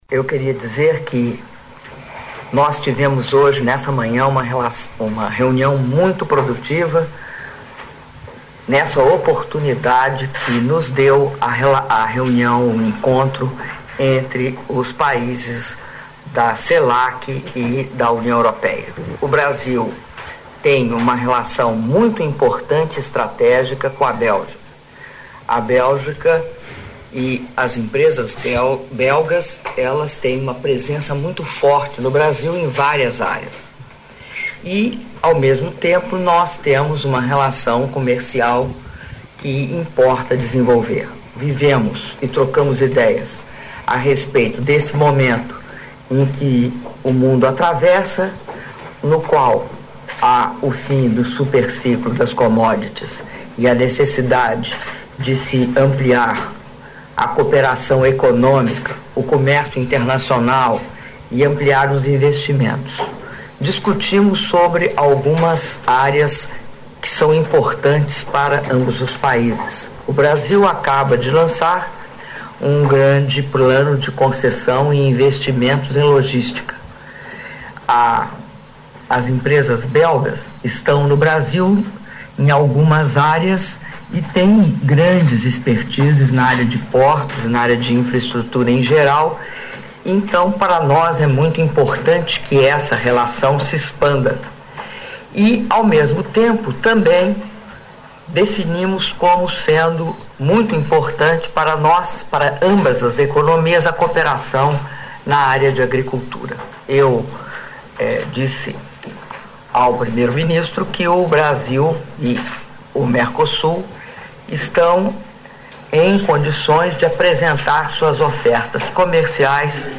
Áudio da declaração à imprensa da presidenta da República, Dilma Rousseff, após reunião com o primeiro-ministro da Bélgica, Charles Michel - Bruxela/Bélgica (4min58s)